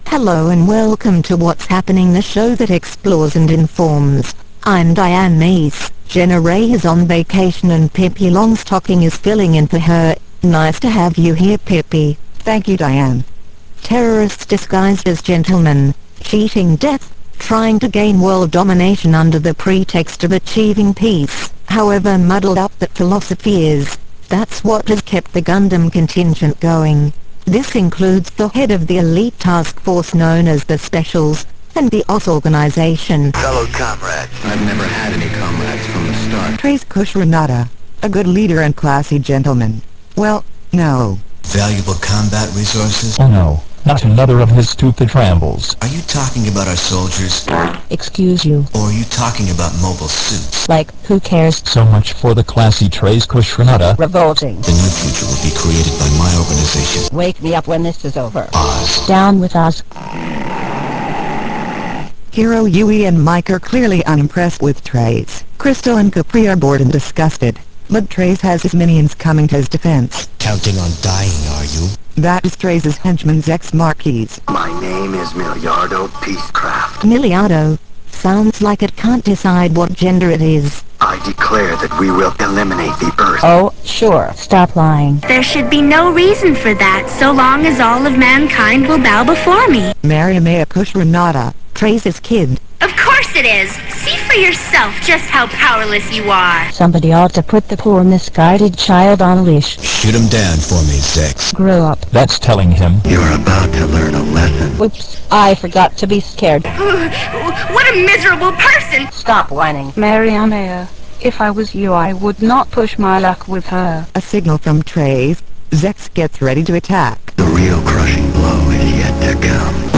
Download or listen to the What's Happening wav to hear the characters talk and interact!